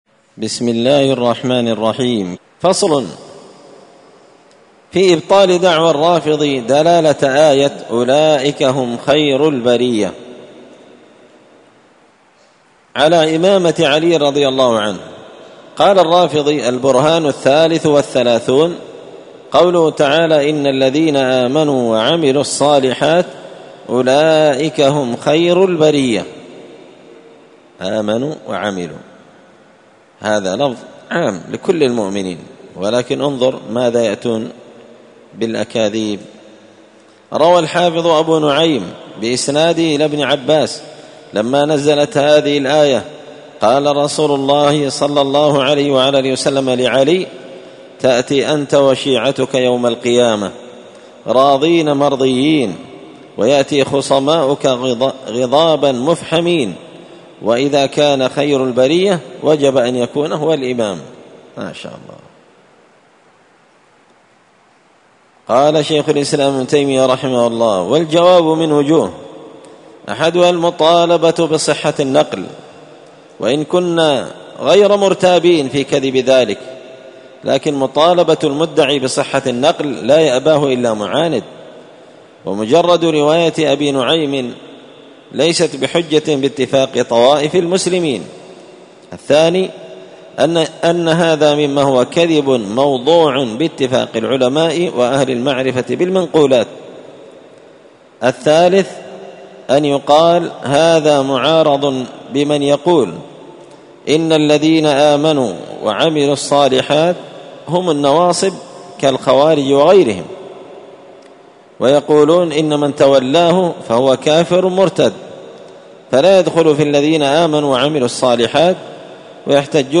الأربعاء 14 صفر 1445 هــــ | الدروس، دروس الردود، مختصر منهاج السنة النبوية لشيخ الإسلام ابن تيمية | شارك بتعليقك | 83 المشاهدات
مسجد الفرقان قشن_المهرة_اليمن